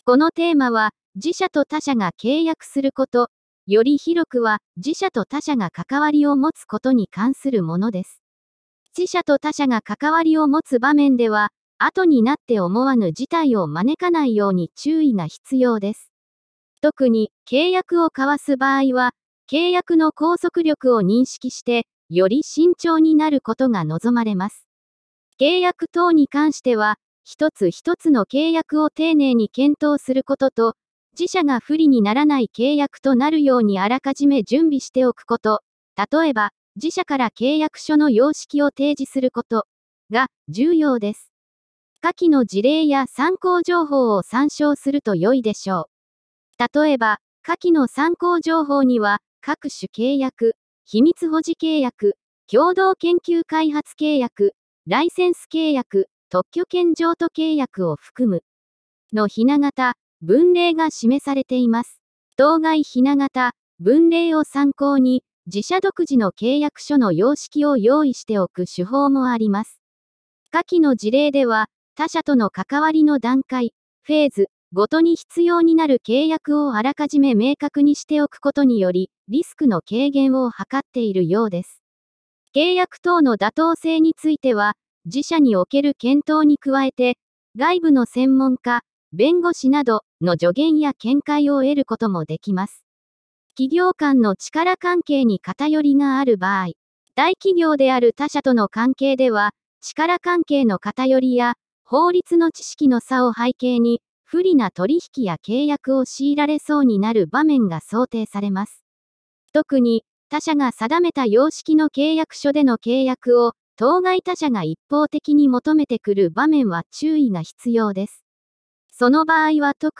テーマの説明音声データ＞＞